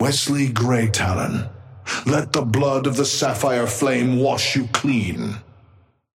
Patron_male_ally_orion_start_03.mp3